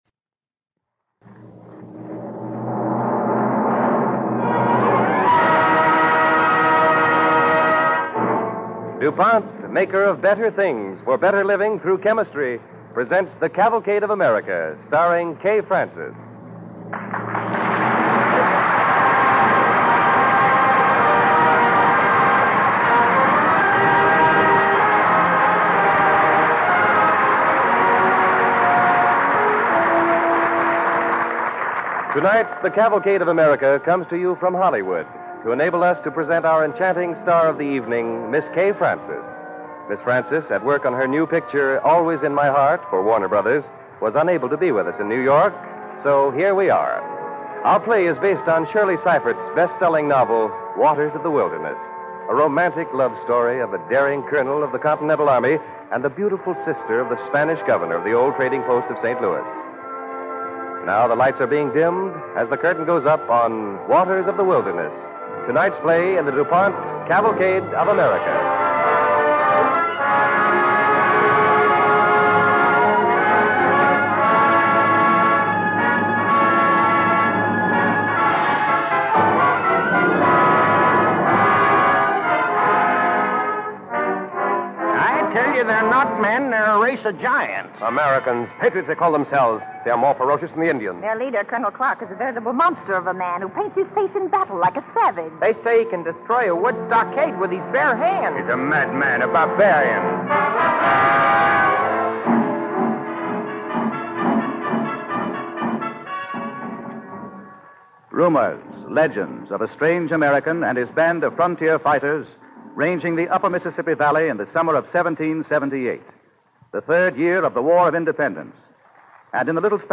Waters of the Wilderness, starring Kay Francis and Gale Gordon